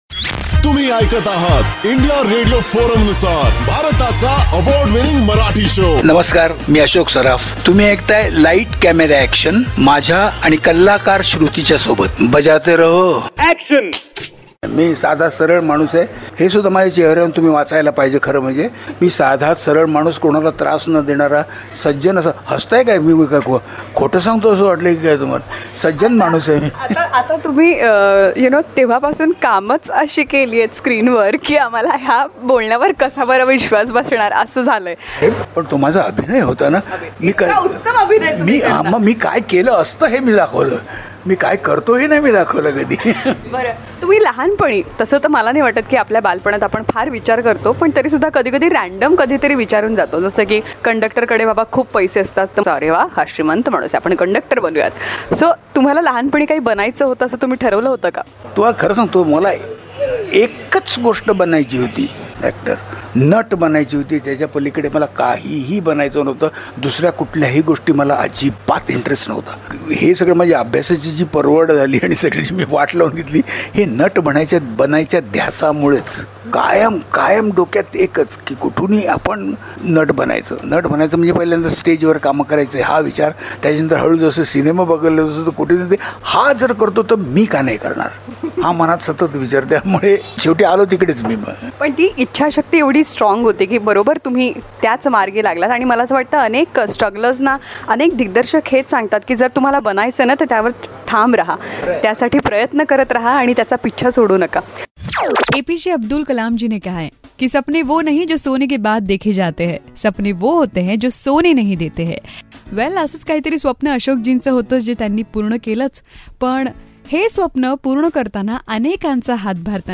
VETRAN ACTOR ASHOK SARAF TALKS ABOUT HIS DREAMS, WHAT HE WANTED TO BE